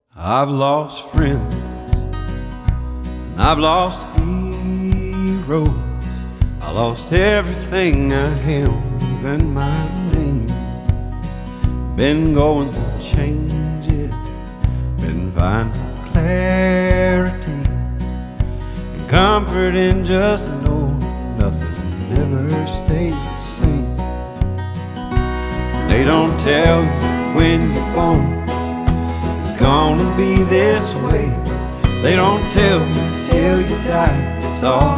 Жанр: Рок / Кантри
Rock, Country, Contemporary Country